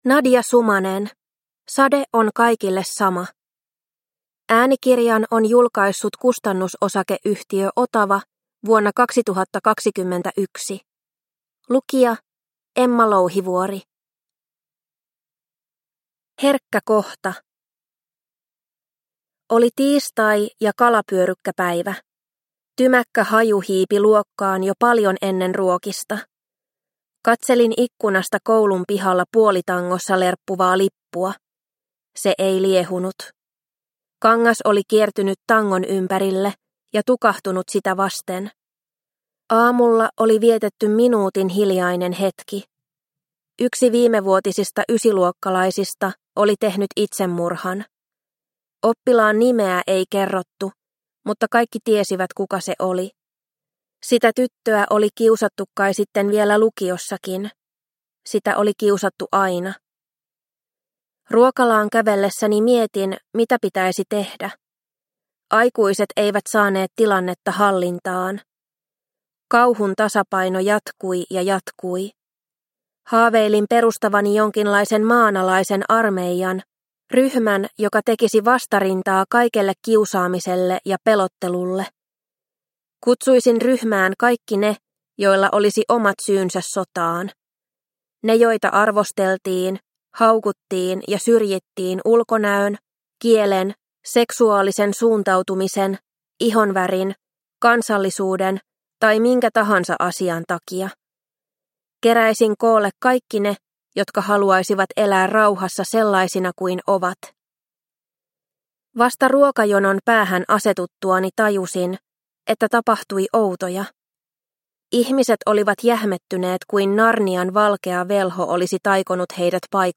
Sade on kaikille sama – Ljudbok – Laddas ner